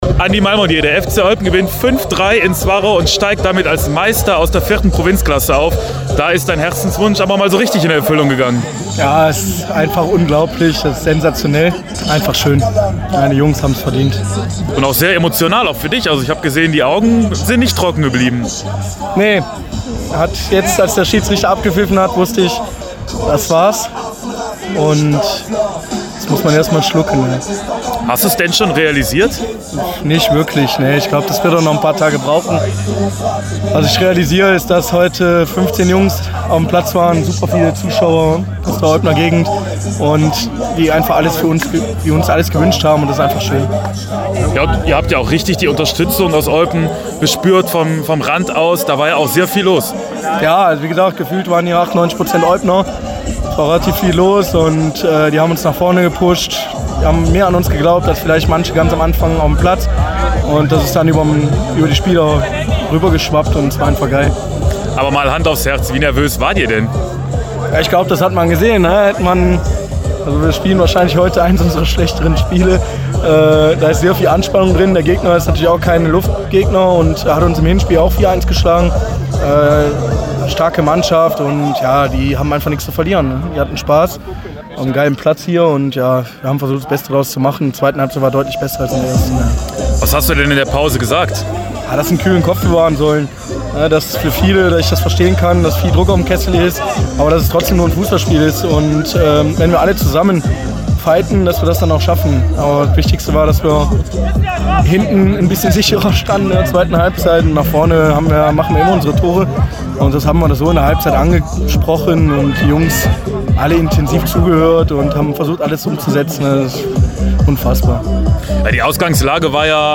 sprach nach dem Spiel mit ihm: